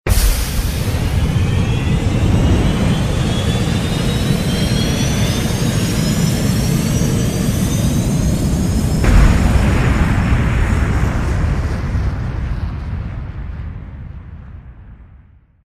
launch8.ogg